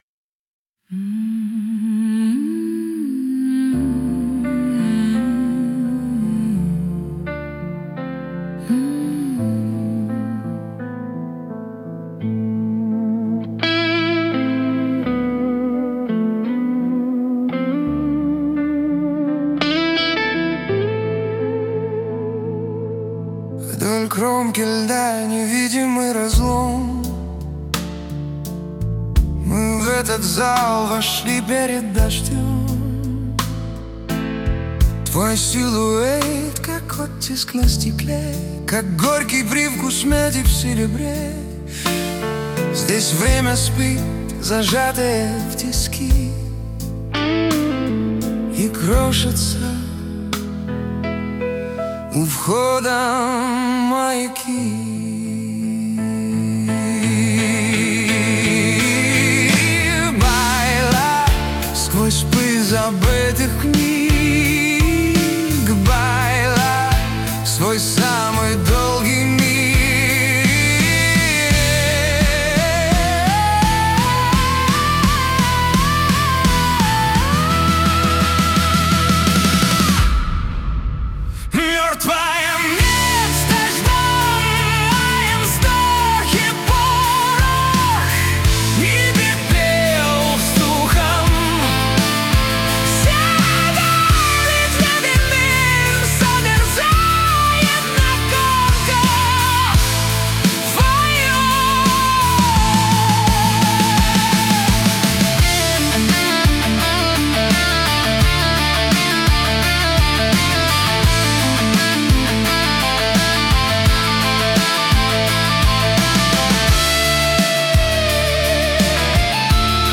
• Жанр: AI Generated
Experimental